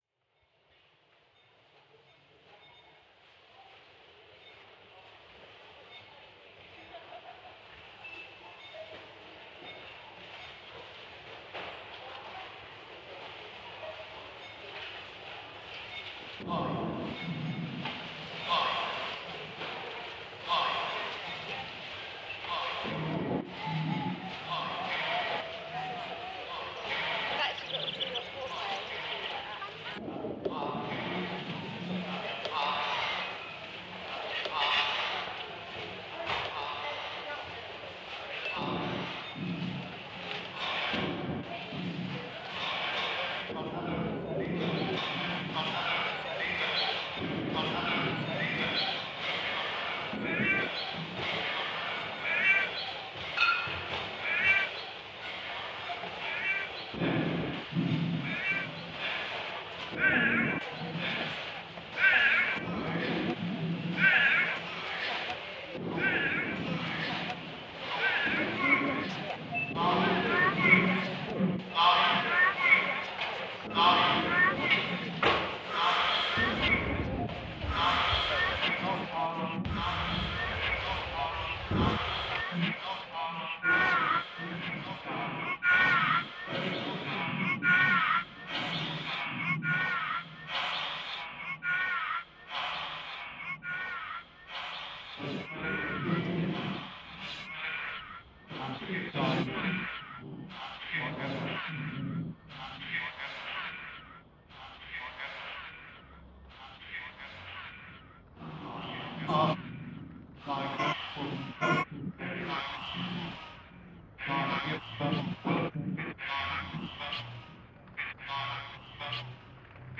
I then took a recording of a recitation from Wells Cathedral and "cut up" the track accordingly. I then used these fragments to "over-paint" a recording from a supermarket checkout queue. From this I moved to perhaps the ultimate modern incarnation of the cut-up aesthetic, Granular synthesis. The granulation of another supermarket recording is overlaid with a reading of my cut up poem.